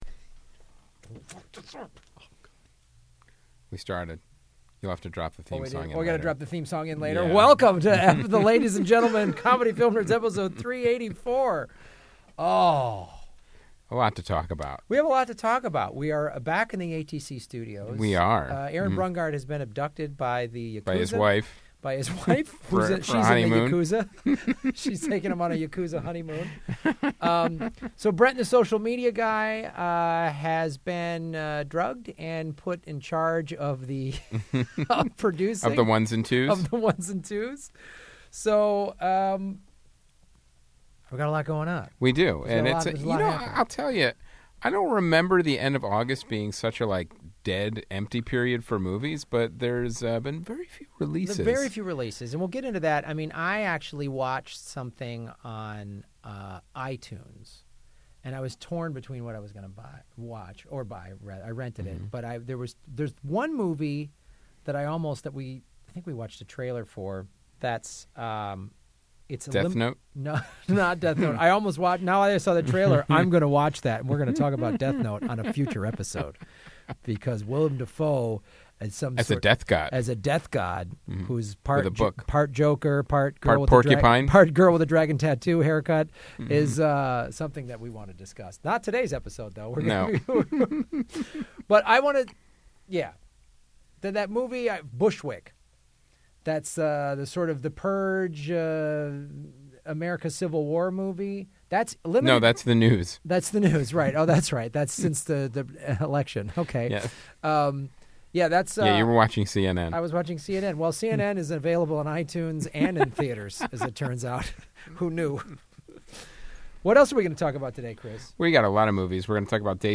into the ATC studios